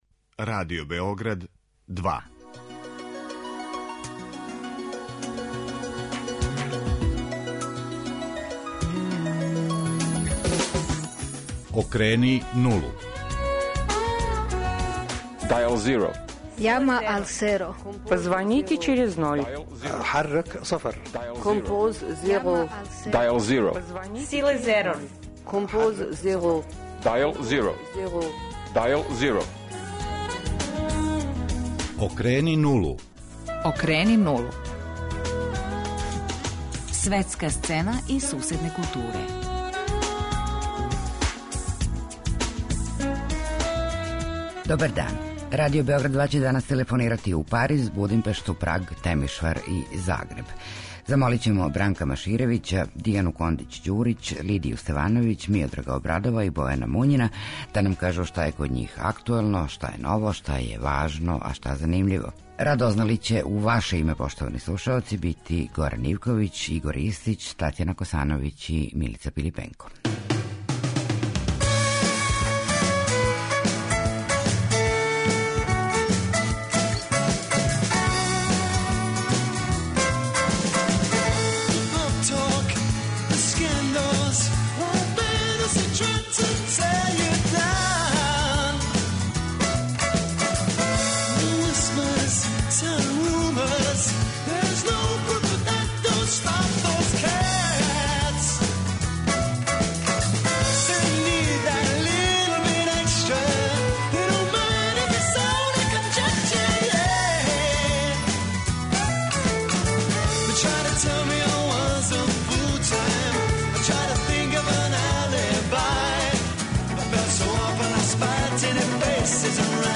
У београдском студију су